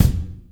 • Bass Drum One Shot F Key 03.wav
Royality free kick sample tuned to the F note. Loudest frequency: 789Hz
bass-drum-one-shot-f-key-03-Tzi.wav